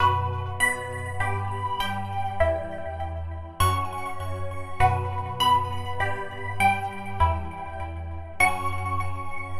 标签： 100 bpm Rap Loops Piano Loops 1.62 MB wav Key : Unknown FL Studio
声道立体声